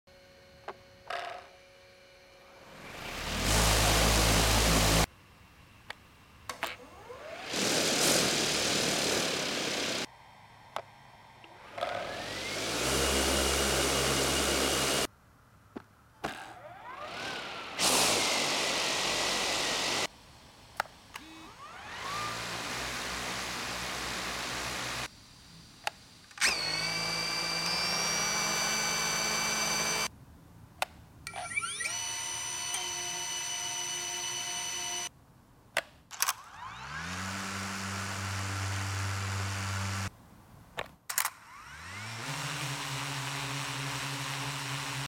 Drone ASMR 2 DJI Sound Effects Free Download